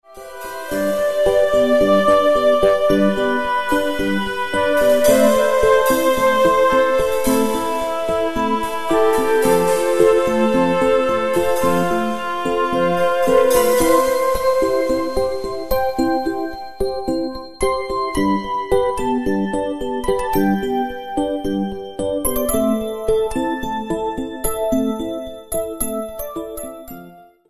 Relaksacyjna Muzyka Etniczna.